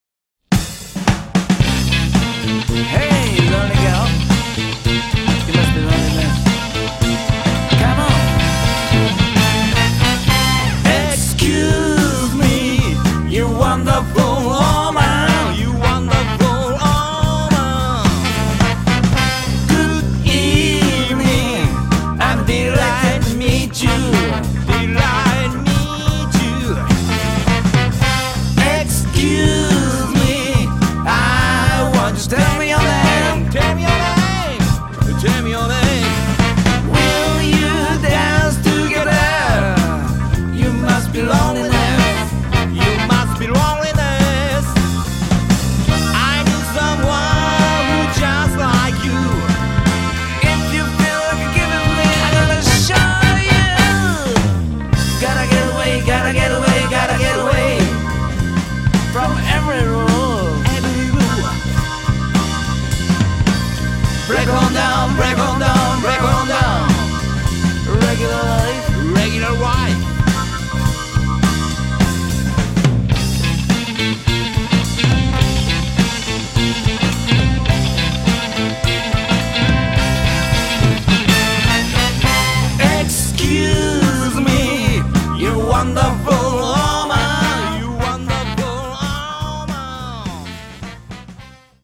ブルースロックやソウルミュージックに影響を受けたオリジナル曲を製作し活動する神戸のブルースロックバンド。
【試聴音源:ROCK】